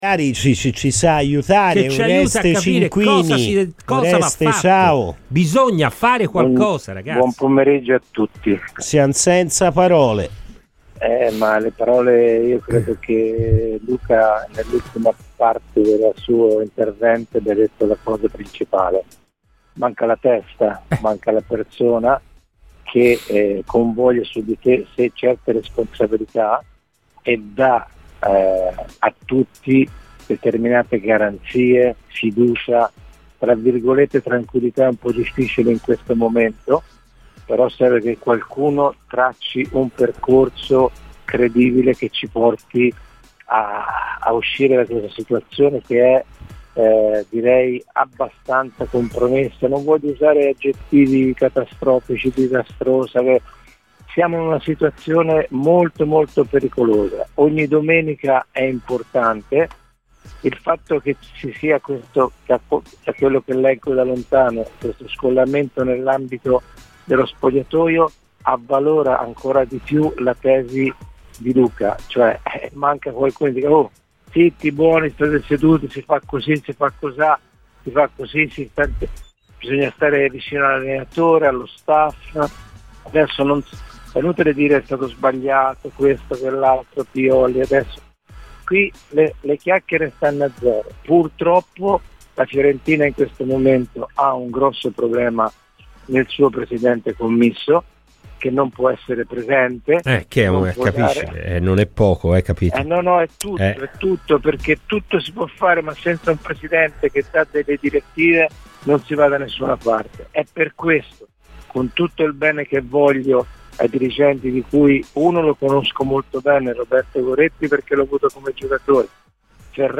Ascolta l'intervista completa su Radio FirenzaViola